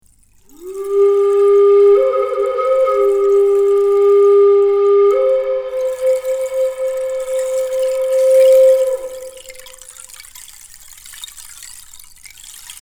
resonance-extrait-11-flute-et-ruisseau.mp3